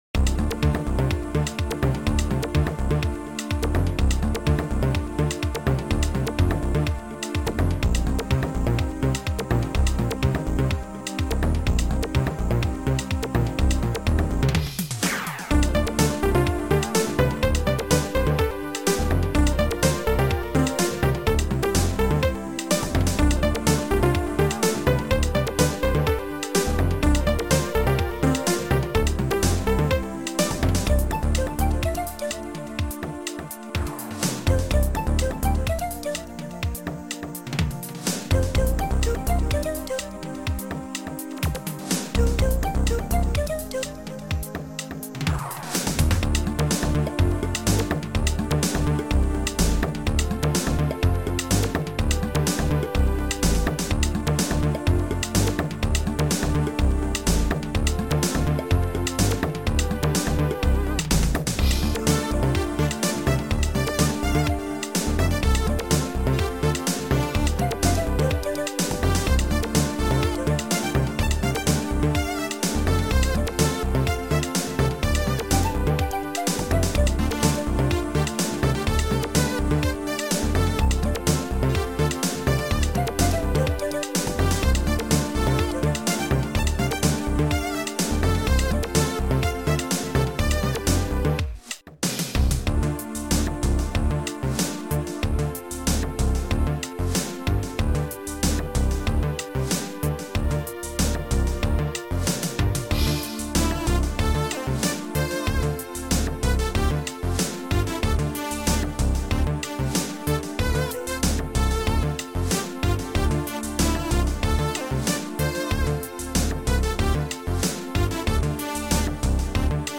Sound Format: Noisetracker/Protracker
Sound Style: Ambient